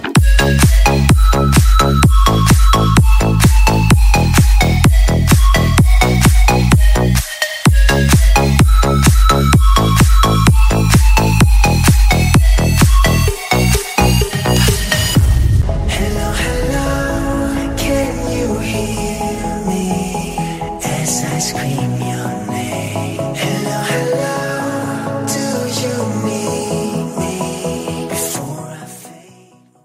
громкие
remix
энергичные
Melbourne Bounce